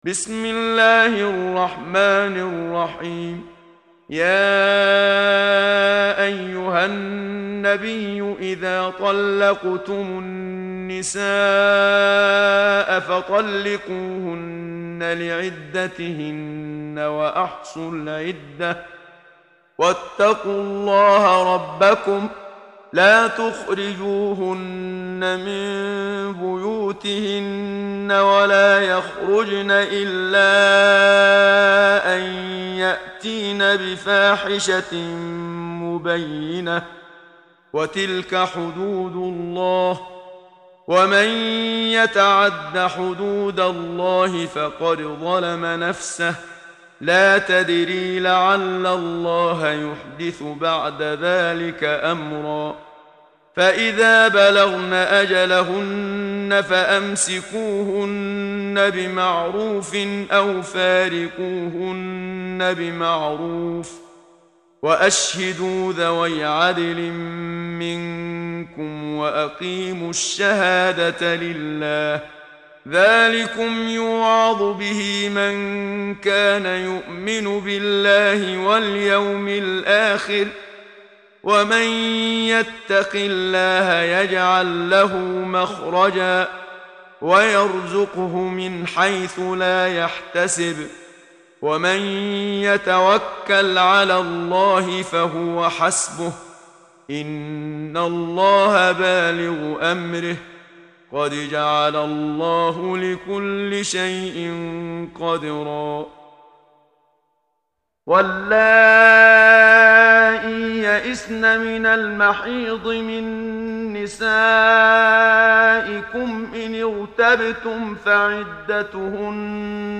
محمد صديق المنشاوي – ترتيل